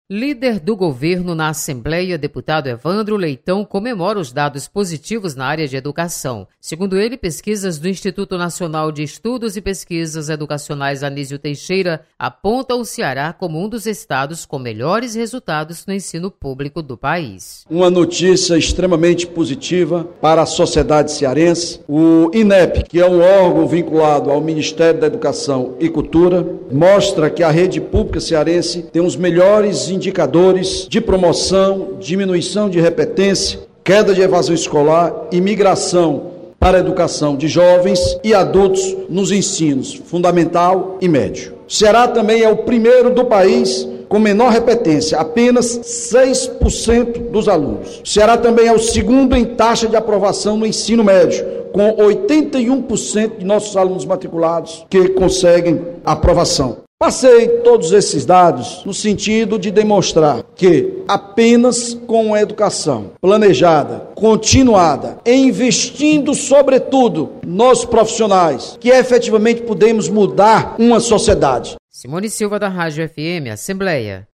Evandro Leitão comemora resultados da educação no Ceará. Repórter